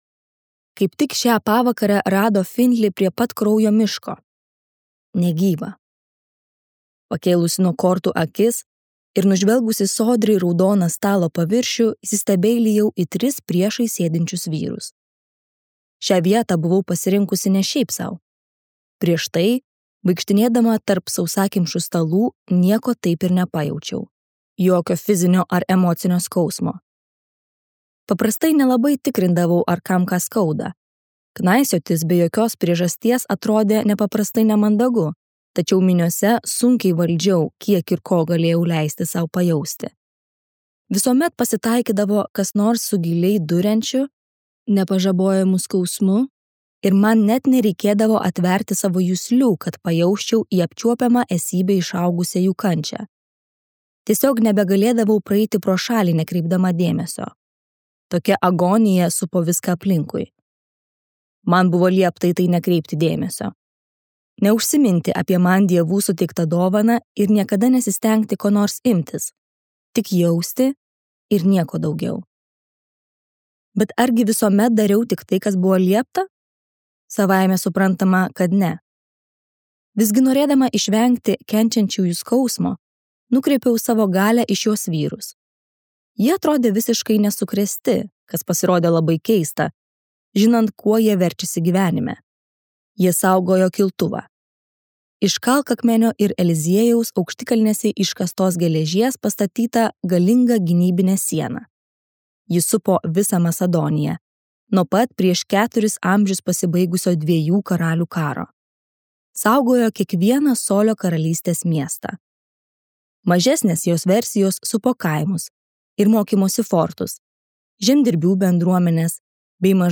Jennifer L. Armentrout audioknyga „Iš kraujo ir pelenų“ – pirmoji serijos „Kraujas ir pelenai“ dalis. 2020-aisiais metais ji pelnė „Goodreads“ geriausio meilės romano apdovanojimą!